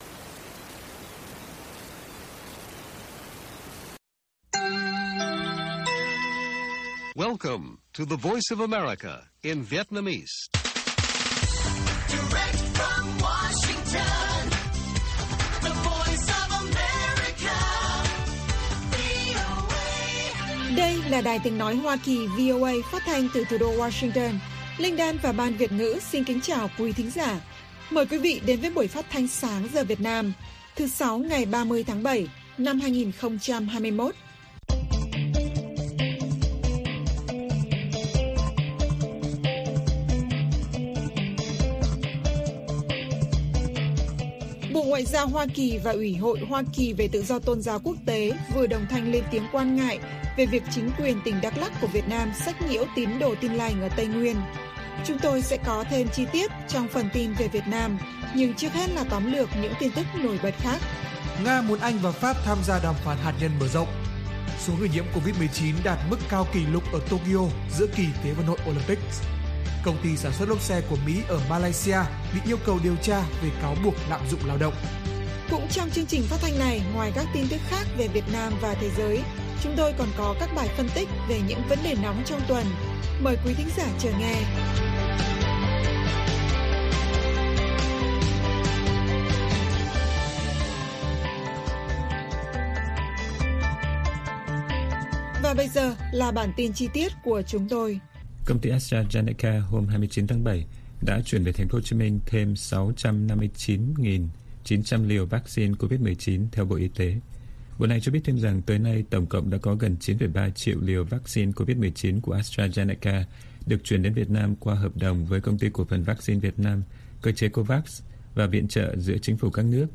Bản tin VOA ngày 30/7/2021